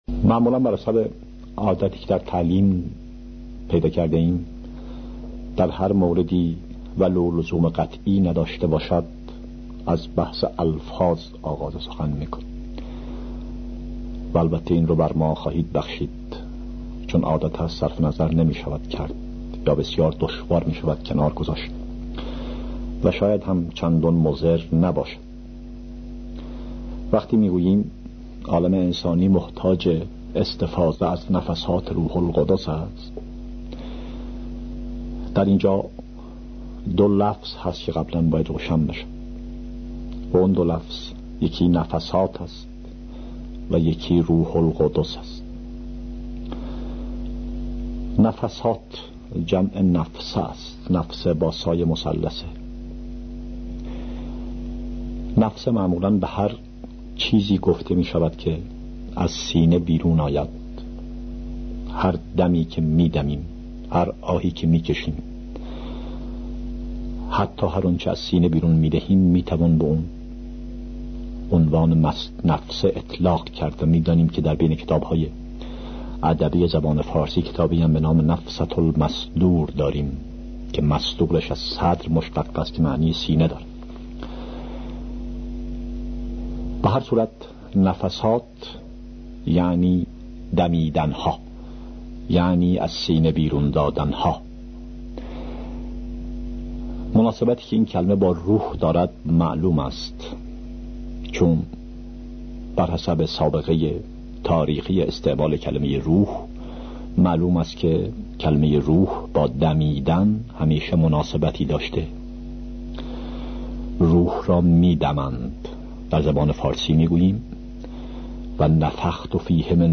Download nafasat_rooholghodos.mp3 سایر دسته بندیها سخنرانی هایی پیرامون عقاید بهائی 16368 reads Add new comment Your name Subject دیدگاه * More information about text formats What code is in the image?